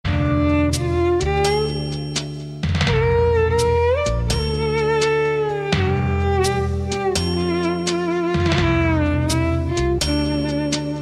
kategori : Classical